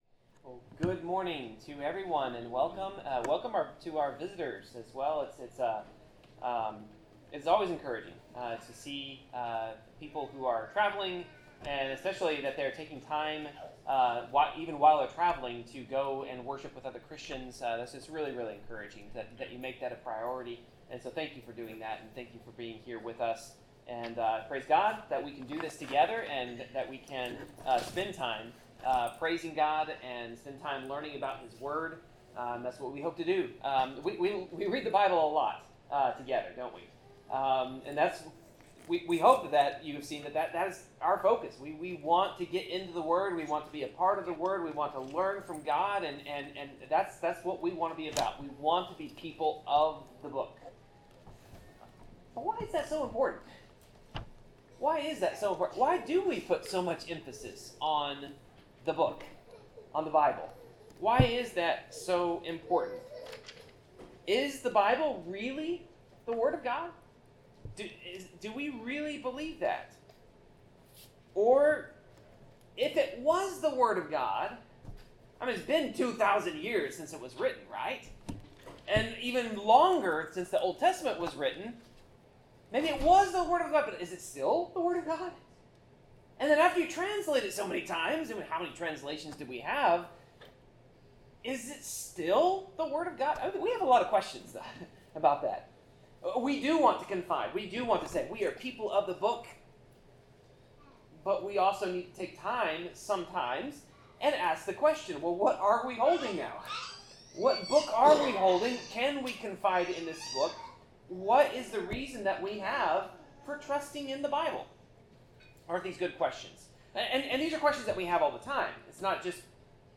Passage: Isaiah 53 Service Type: Sermon